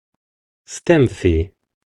It’s pronounced similarly to "selfie," symbolizing the concept of "self."
How_to_pronounce_STEMFIE.ogg